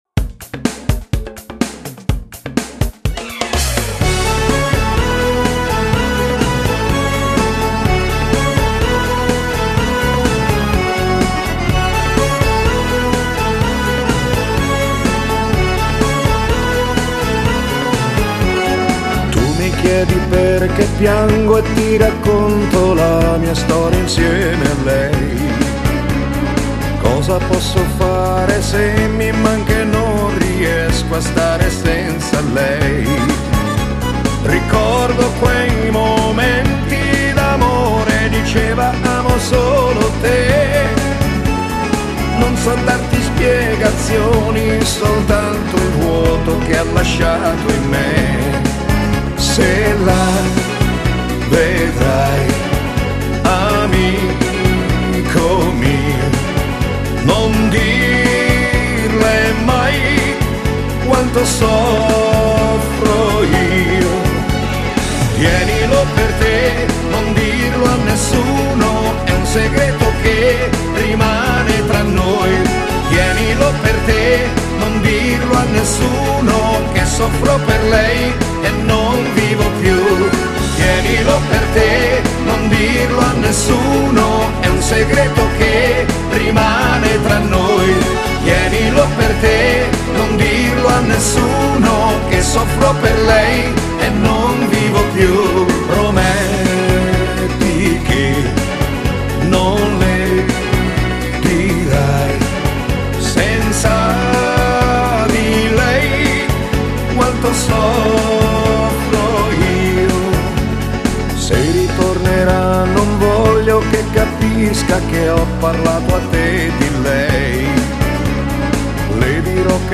Genere: Beguine moderna